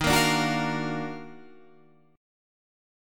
Listen to D#mM9 strummed